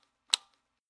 ru556_firemode.ogg